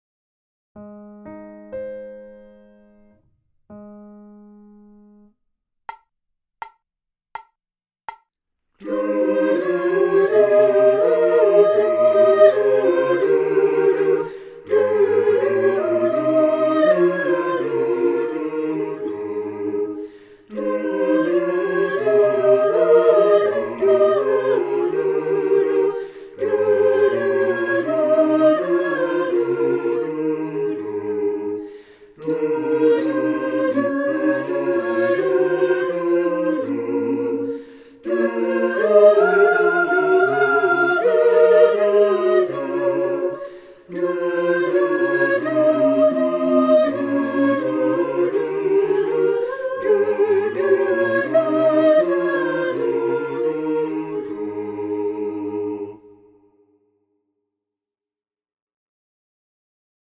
Tenor
Tenor.mp3